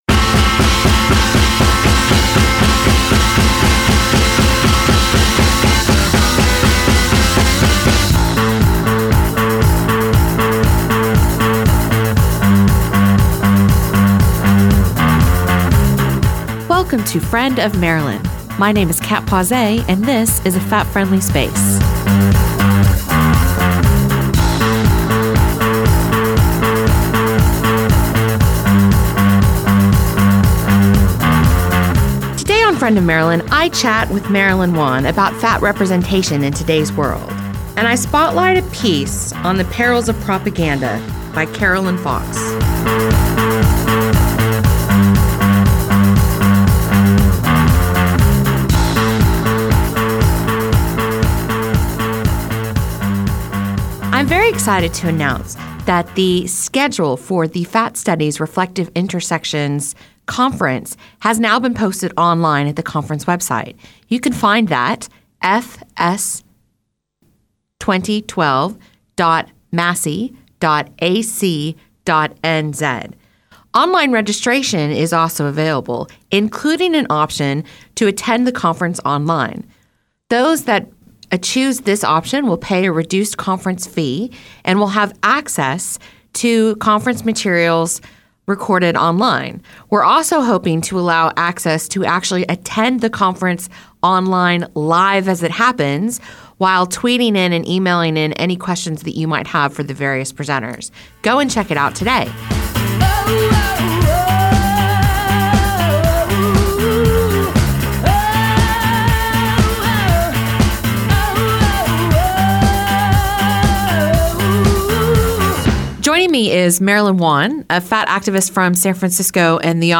This episode has been edited from version originally broadcast to remove music by Romeo Void as rights and licences were not obtained by Manawatū Heritage.